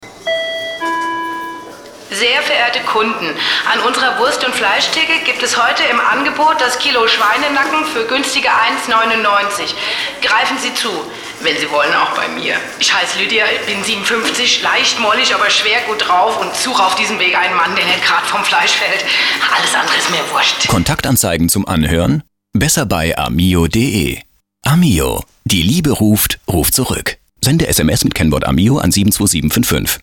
rauchige, knarzig-raue, sehr tief gehende Blues-Stimme, gut für Comic/Kunstfiguren (Lenya / Knef), englisch fliessend außergewöhnliches, mitreissendes Lachen, Kunststimmen und Imitation von Dialekten
Sprechprobe: Werbung (Muttersprache):
female German voice over artist, deep and rough voice preferably comics, video-games, strange characters. blues-singer. English fluent